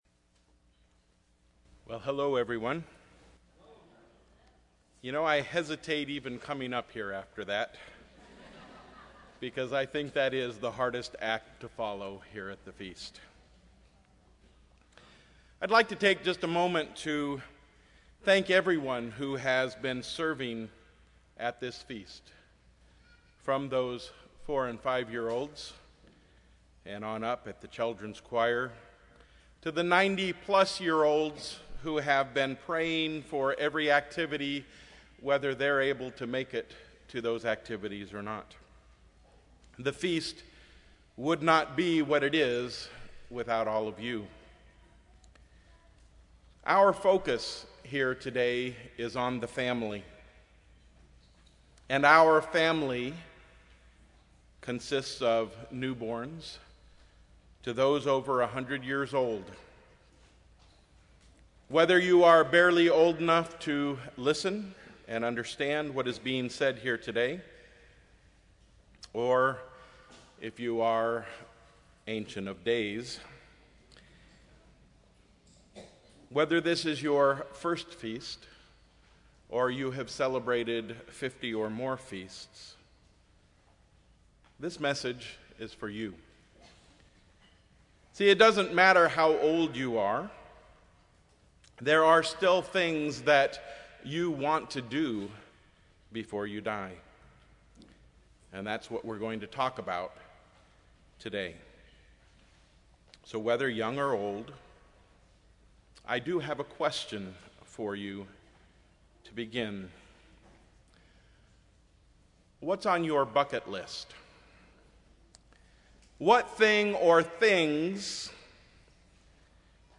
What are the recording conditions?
This sermon was given at the Oceanside, California 2015 Feast site.